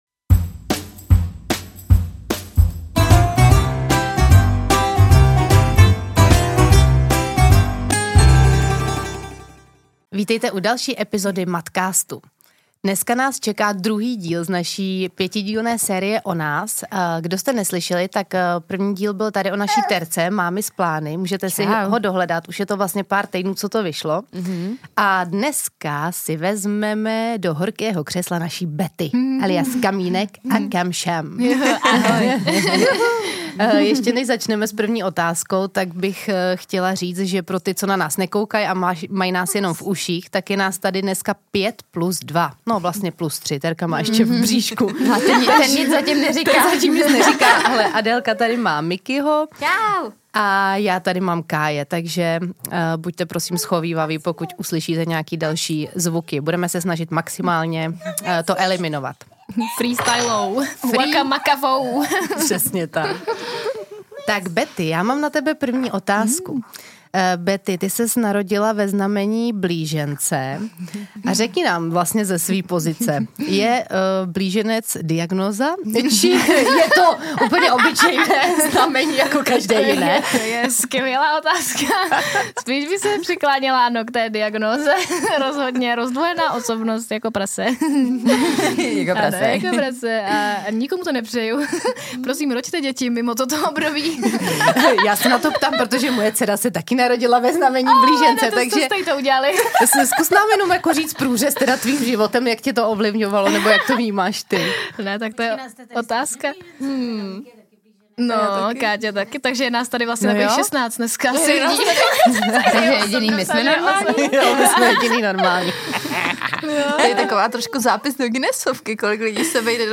Tento díl bude jednoznačně patřit mezi ty nejvíc smějící!